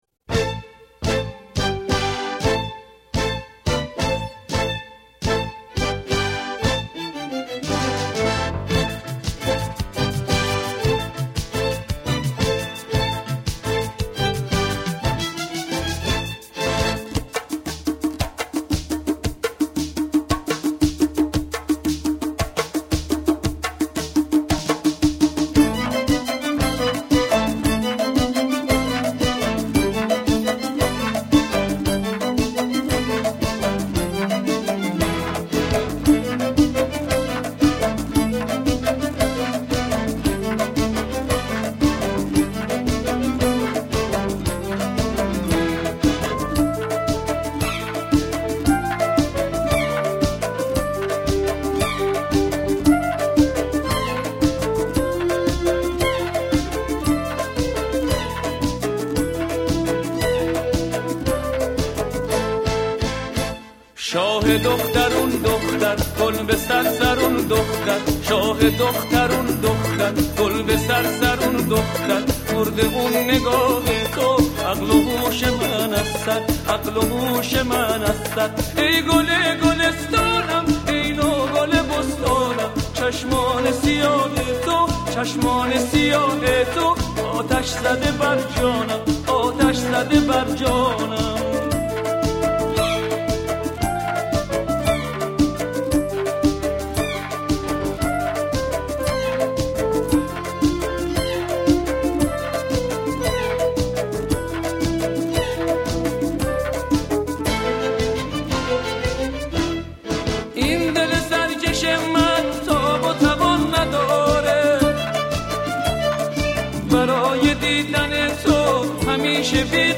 آهنگ نوستالژیک آهنگ عاشقانه